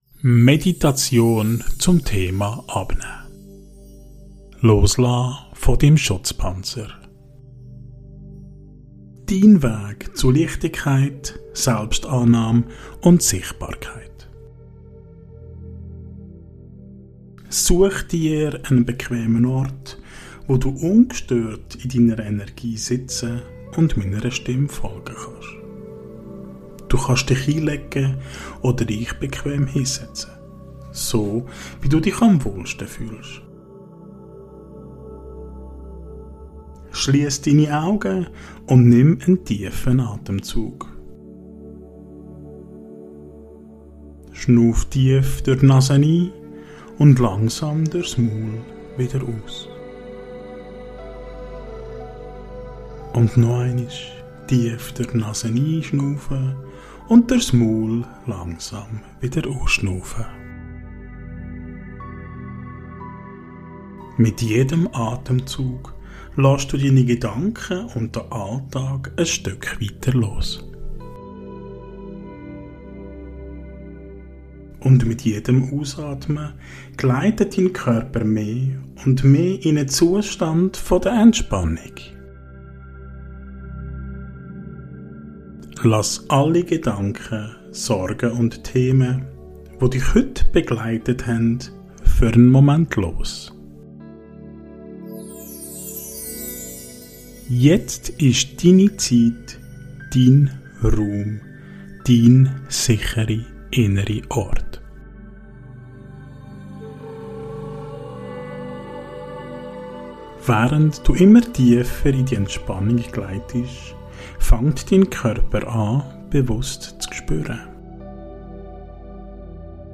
Beschreibung vor 6 Monaten Meditation zum Thema abnehmen - Loslassen des Schutzpanzers Dein Weg zu Leichtigkeit, Selbstannahme und Sichtbarkeit In dieser geführten Meditation begleite ich dich Schritt für Schritt auf deinem Weg zu mehr innerer Freiheit. Über sanfte Atemübungen findest du Ruhe, richtest deine Aufmerksamkeit auf deinen Körper und spürst, wo du vielleicht noch alten Ballast oder deinen „Schutzpanzer“ festhältst. Mit einer liebevollen Visualisierung darfst du diesen Panzer Schicht für Schicht loslassen – und so Raum schaffen für Vertrauen, Sichtbarkeit und dein wahres Selbst.